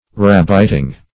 Rabbiting \Rab"bit*ing\, n. The hunting of rabbits.